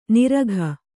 ♪ niragha